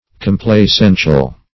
Search Result for " complacential" : The Collaborative International Dictionary of English v.0.48: Complacential \Com`pla*cen"tial\, a. Marked by, or causing, complacence.